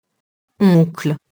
oncle [ɔ̃kl] nom masculin (lat. avunculus)